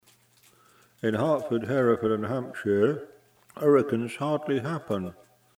Heh! Managed to get a echo on the recording!
The MP3 clip is HPF'ed at 160Hz (Samplitude para'eq_) because the mic gives a spike at 100Hz and steeply rising LF "noise" below that, down to DC? Apart from that I think the quality is not 1/2 bad for 40quid all up? Noise is on a par with a decent (but not Nakawhotsit) cassette machine.